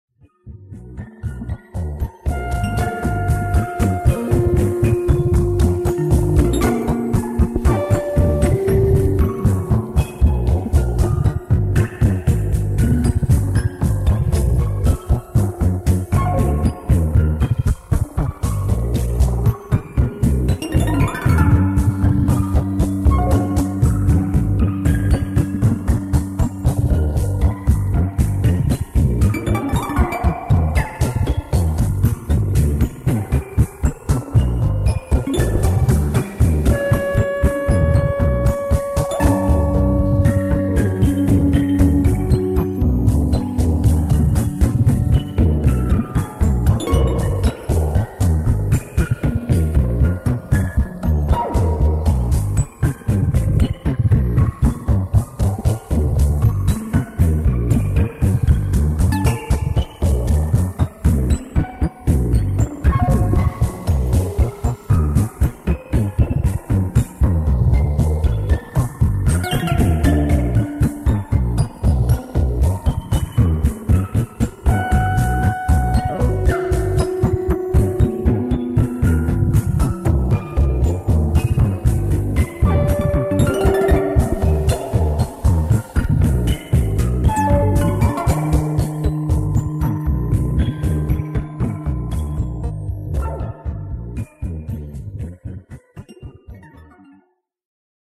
The complete set of my modified Synthi A TKS